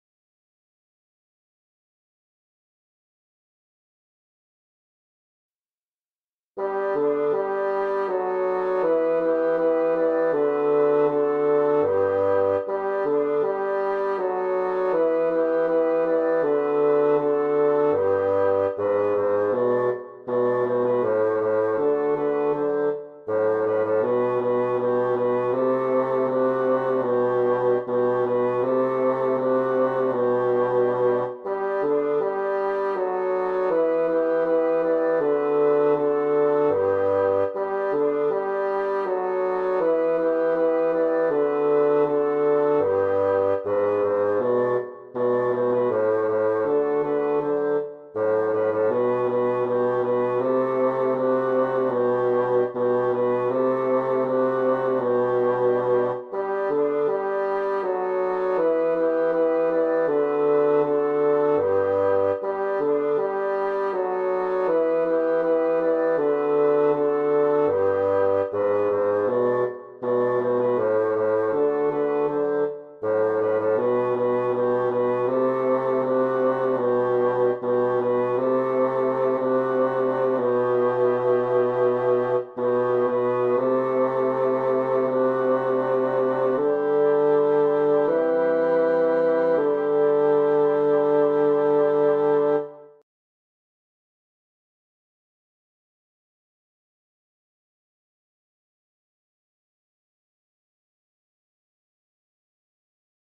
MP3 version instrumentale (les audios sont téléchargeables)
Basse